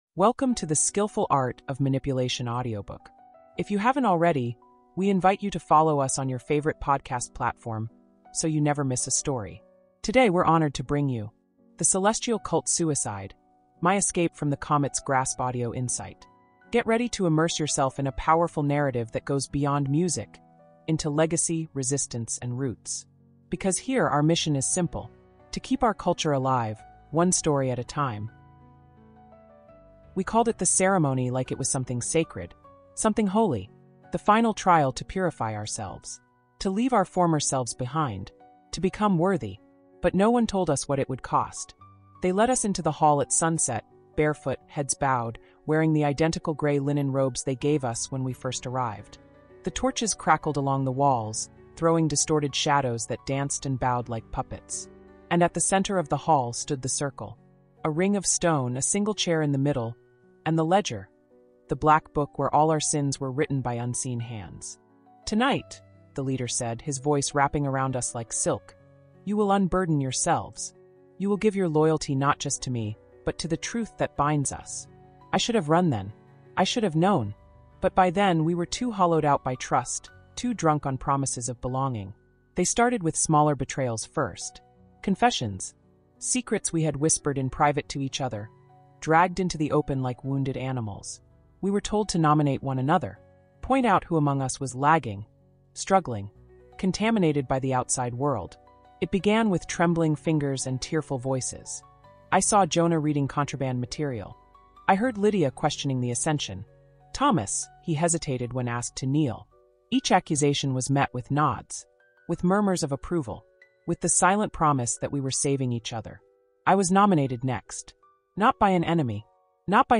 This emotionally charged insight pulls you into the darkest heart of manipulation — where survival becomes sin, and trust becomes the sharpest blade.